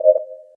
swipe.ogg